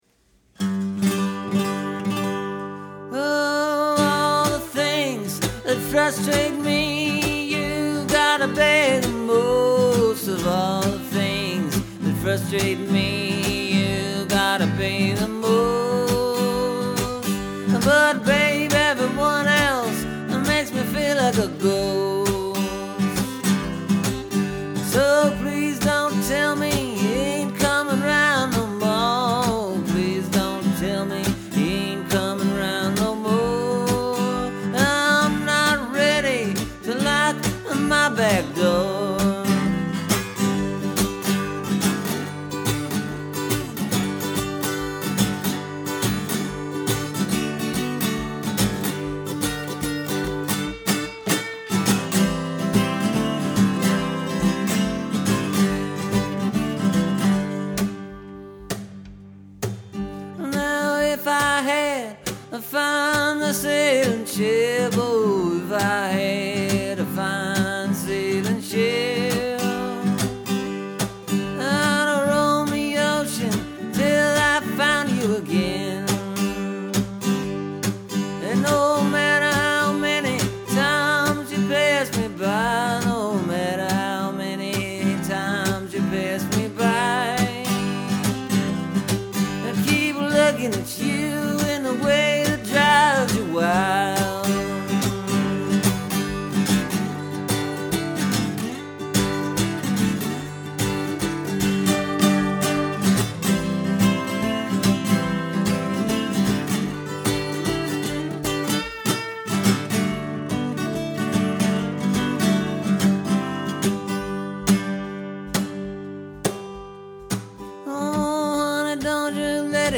It’s in drop-d tuning
It’s just folk music. Pretty simple stuff.